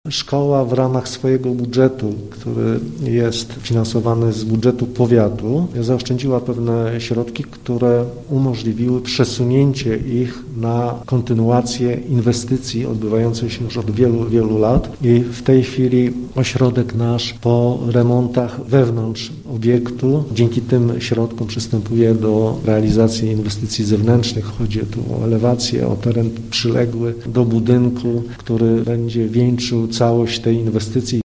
– Zarząd powiatu nowosolskiego wyraził zgodę na przesuniecie w budżecie szkoły pieniędzy na doposażenie nowych pracowni, instalację monitoringu i zagospodarowanie terenu przyległego – informuje Waldemar Wrześniak, wicestarosta nowosolski: